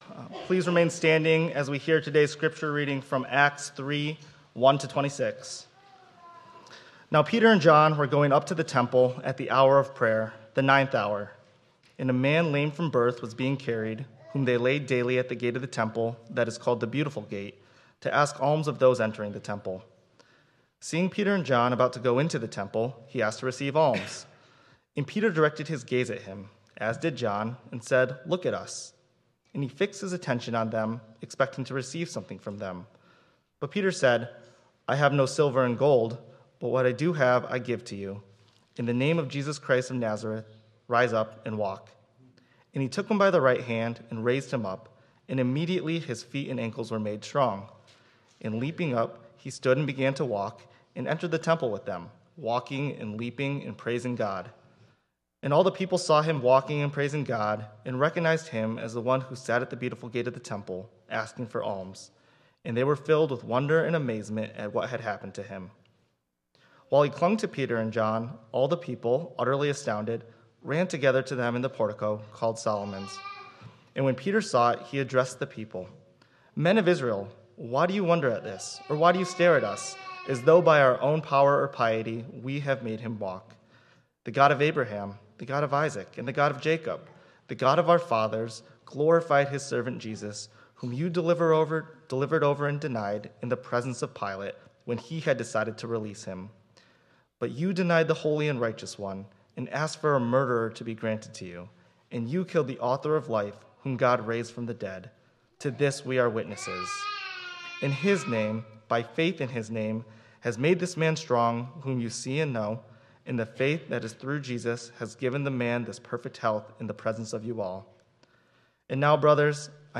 3.1.26 Sermon.m4a